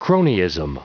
Prononciation du mot cronyism en anglais (fichier audio)
Prononciation du mot : cronyism